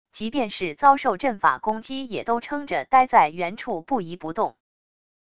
即便是遭受阵法攻击也都撑着待在原处不移不动wav音频生成系统WAV Audio Player